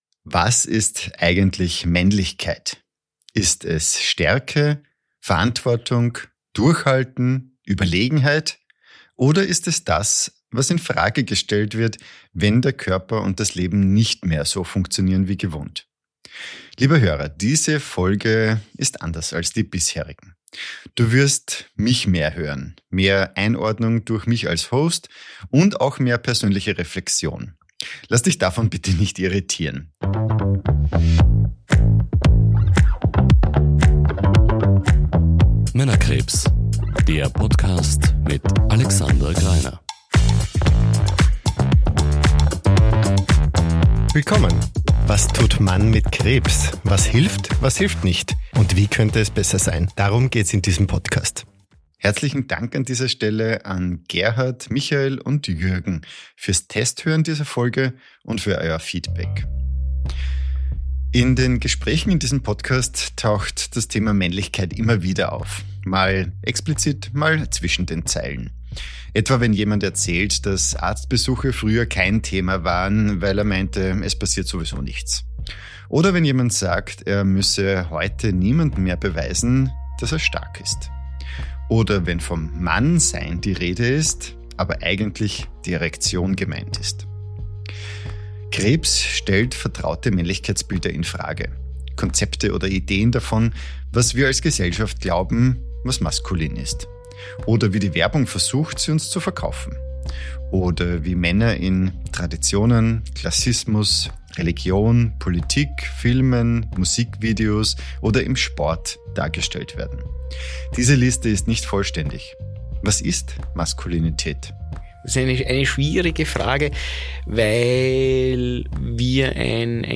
Was bedeutet Männlichkeit, wenn Krankheit Gewissheiten erschüttert? Vier Männer mit Krebs sprechen über Rollenbilder, Stärke, Zweifel und darüber, was vom Mannsein bleibt, wenn nichts mehr funktionieren muss.